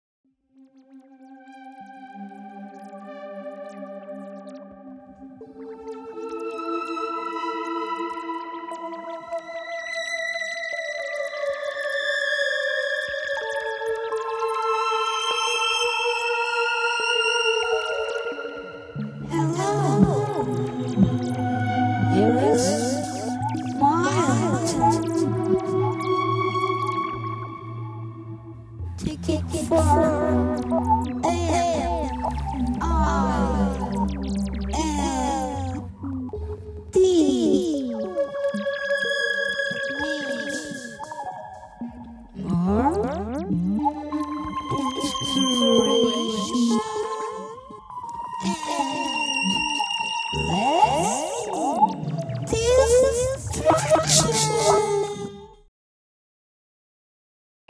Gitarre, Backing-Vocals
Bass, Backing-Vocals
Schlagzeug
Lead-Gesang